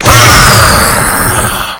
sentry_rocket.wav